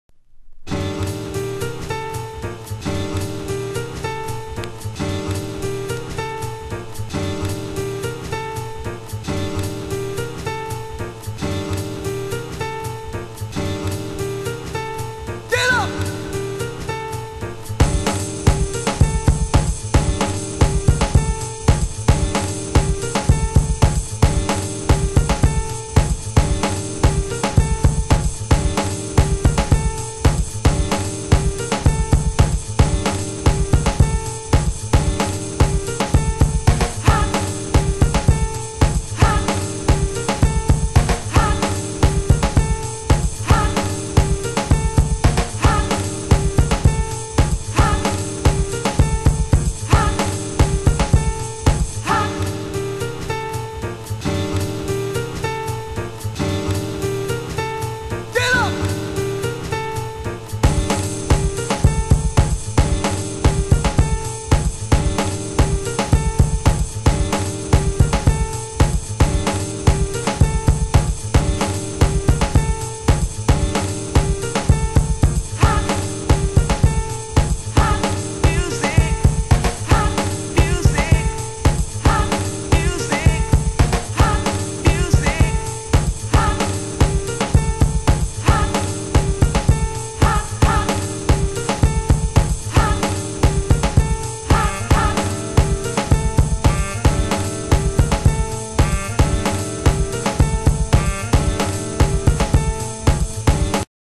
ハウス専門店KENTRECORD（ケントレコード）